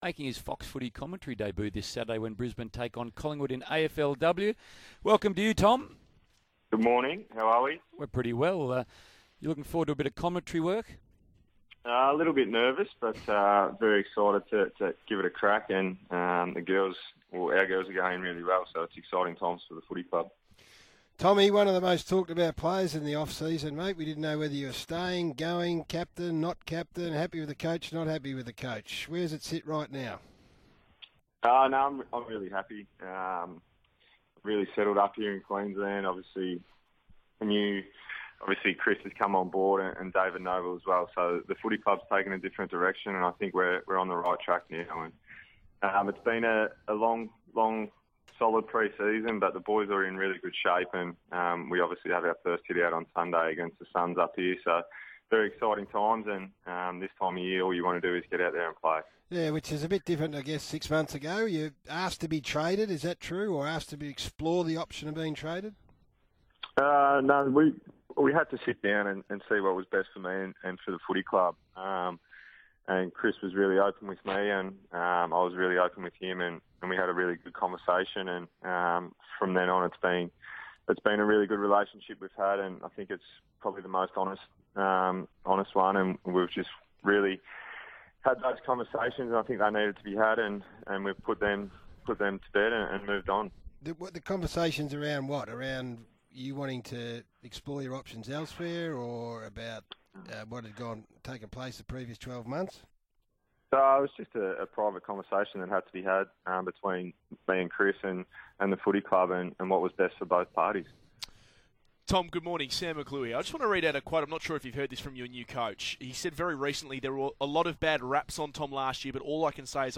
Find out what Brisbane Lions midfielder Tom Rockliff had to say on SEN this morning.